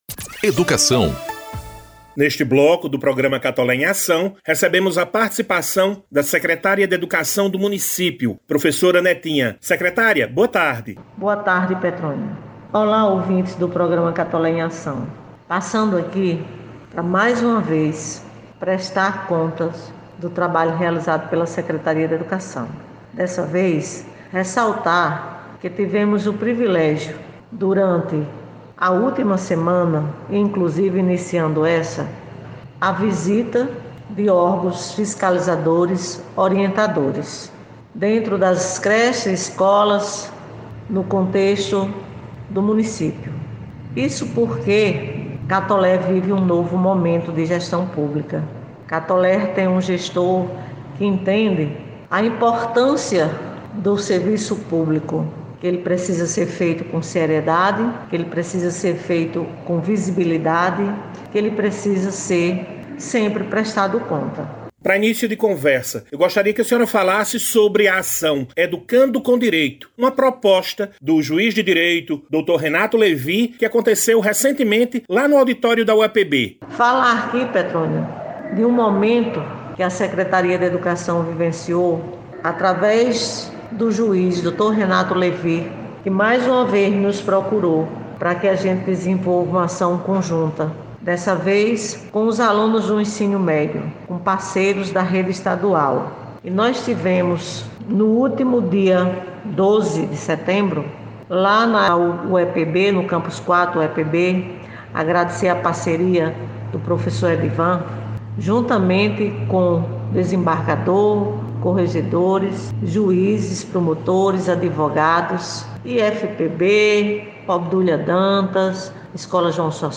C - ENTREVISTA - Sec. Maria Antonia Neta (Educação) Texto